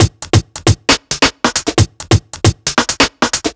Shuffle Break 1 135.wav